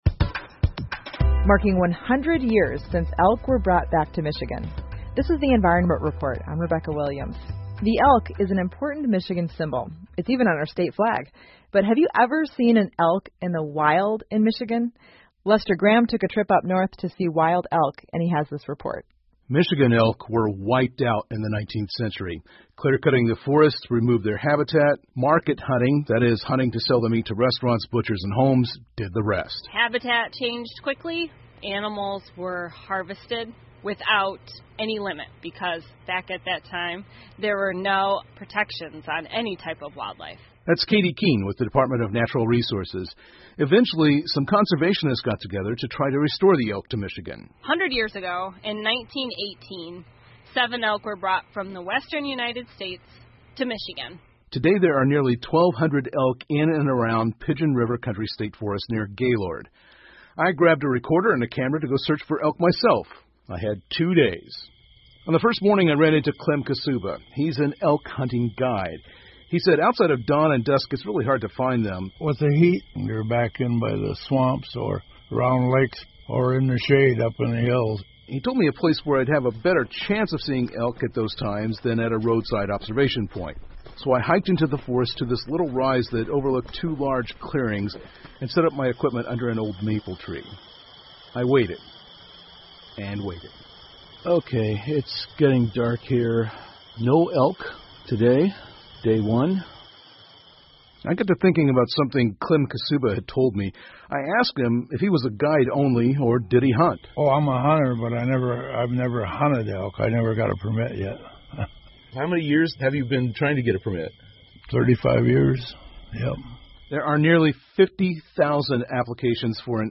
密歇根新闻广播 麋鹿返回密歇根已100年 听力文件下载—在线英语听力室